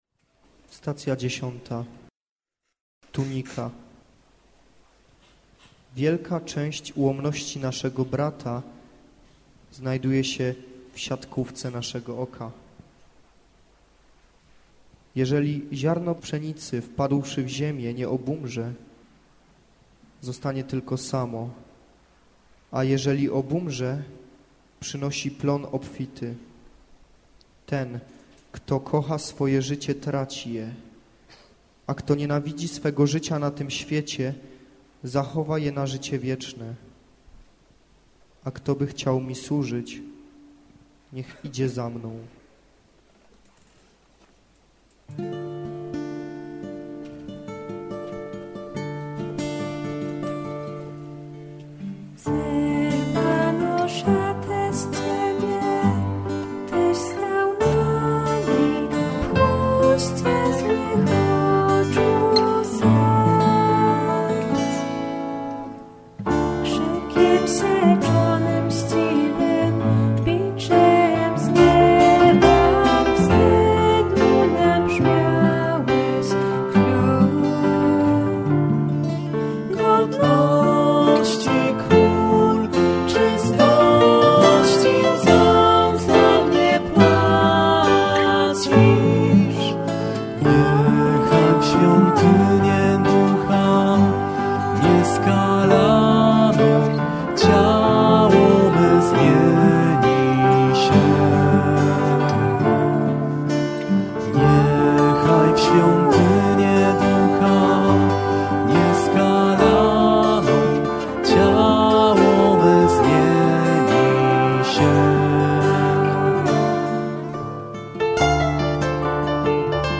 WokalGitaraKeyboard